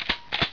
sample_bringer_shotgun.wav